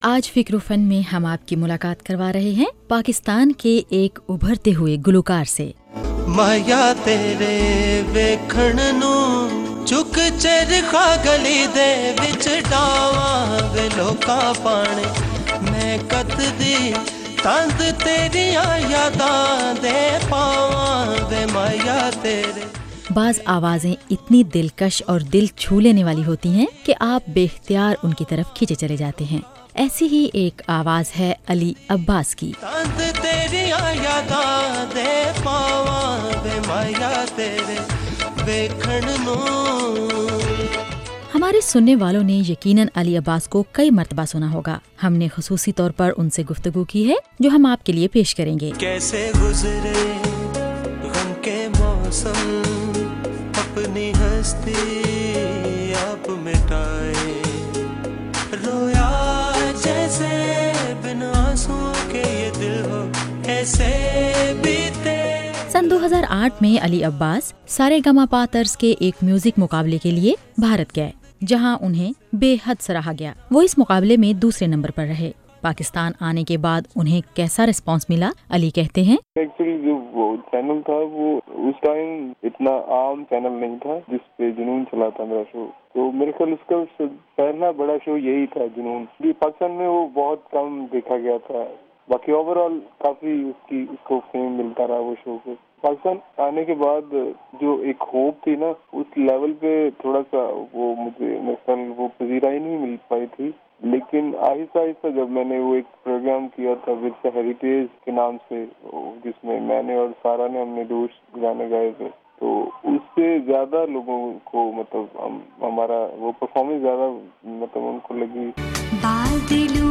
خصوصی انٹرویو